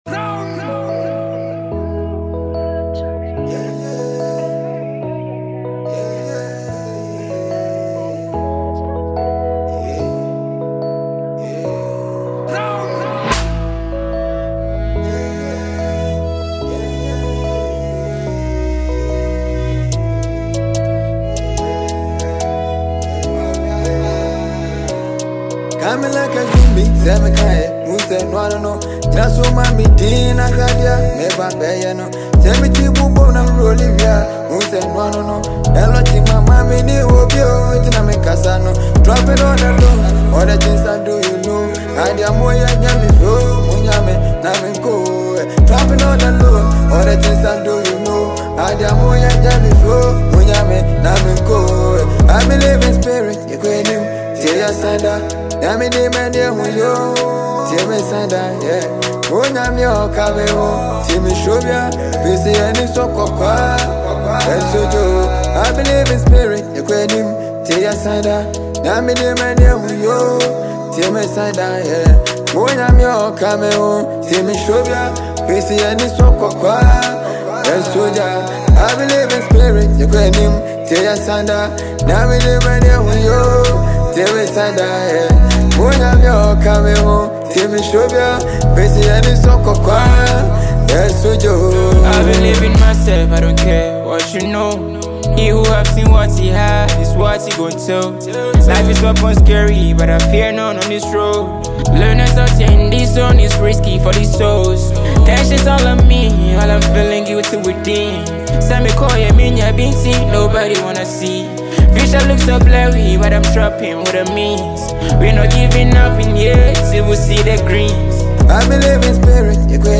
Sensational Hip Pop Artiste
heartfelt Afrobeat-inspired track
adds soulful depth with a compelling vocal performance.